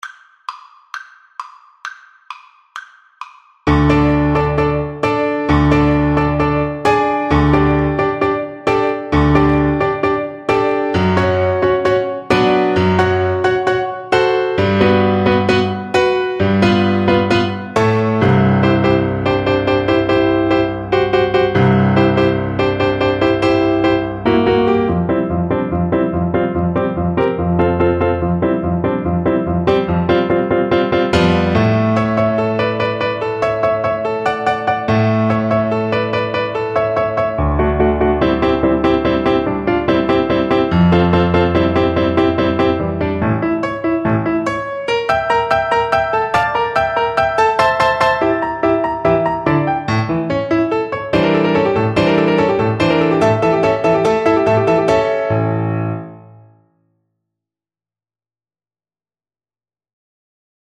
Allegro =132 (View more music marked Allegro)
2/4 (View more 2/4 Music)
Classical (View more Classical Violin Music)